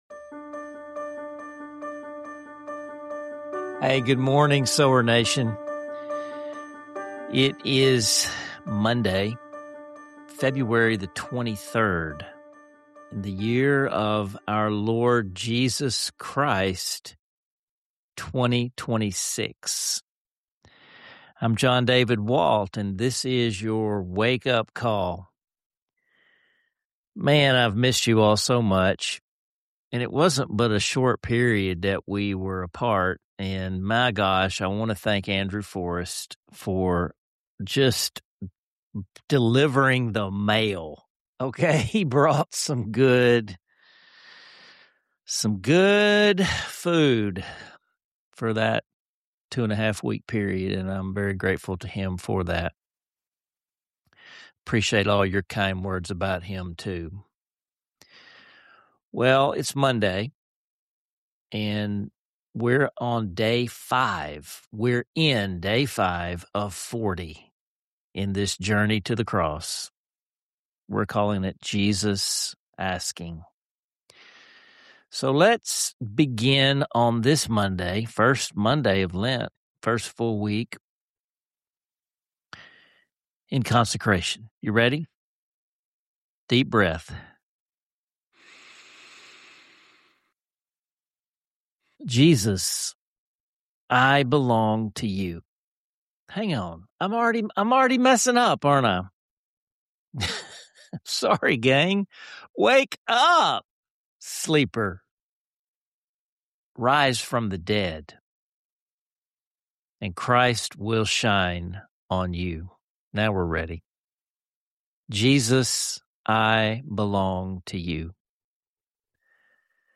16:37 Singing “Nothing But the Blood” Together